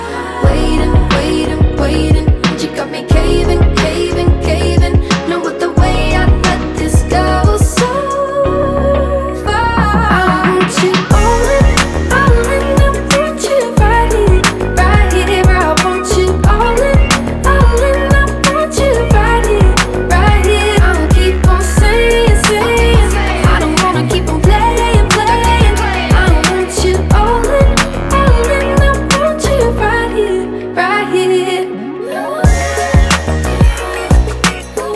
Плавные вокальные партии
чувственный бит
Глубокий соул-вокал и плотные хоровые партии
Жанр: R&B / Соул